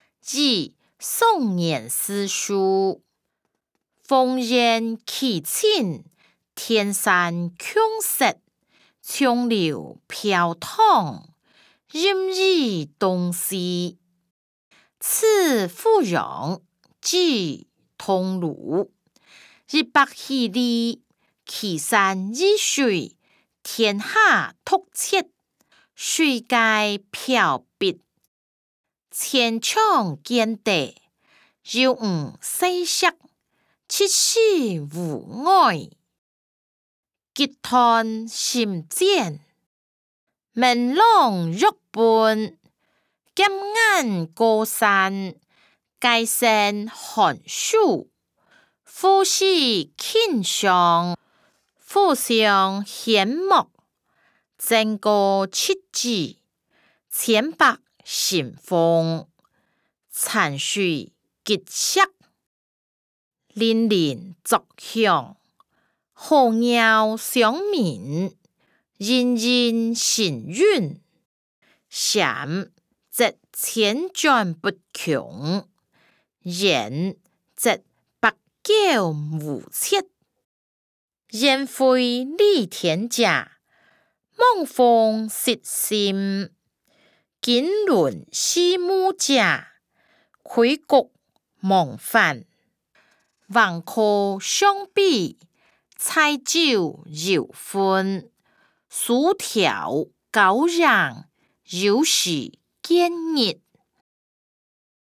歷代散文-與宋元思書音檔(大埔腔)